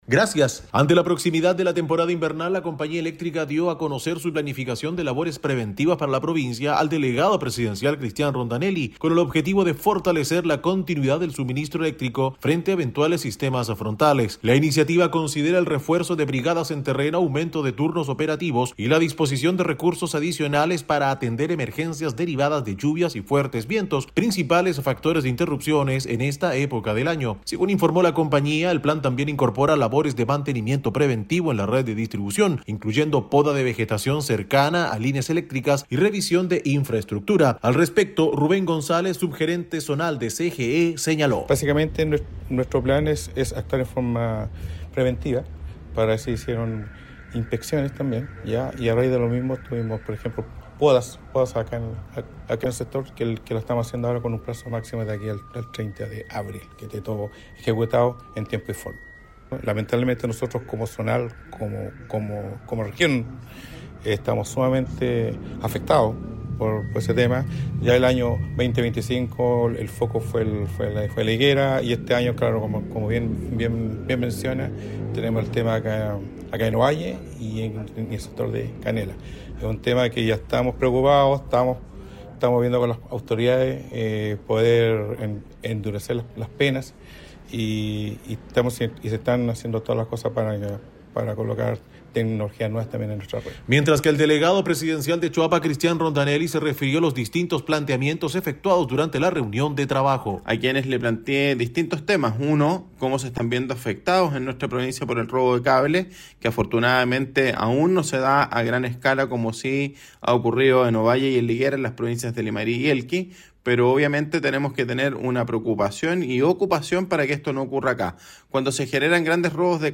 Despacho-Radial-CGE-Plan-Invierno-2026.mp3